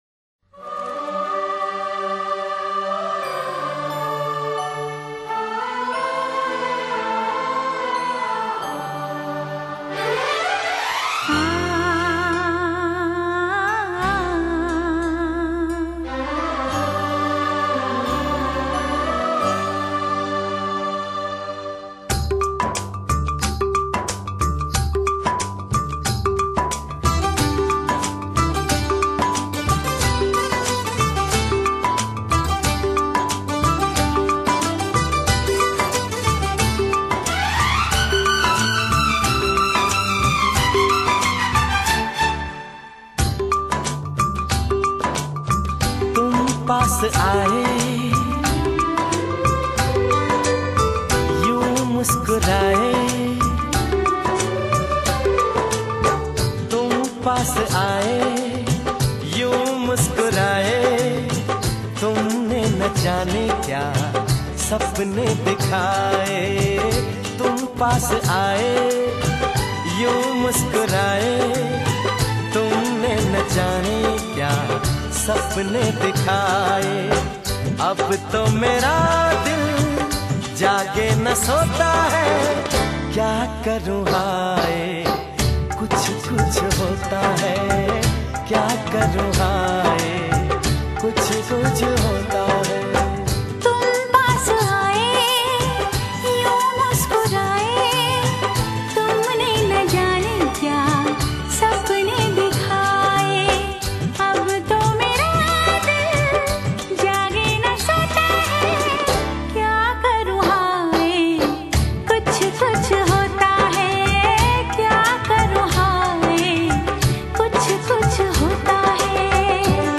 Индийская музыка
05 - индийская музыка.mp3